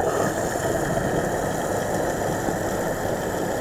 subatomicfuelpump.wav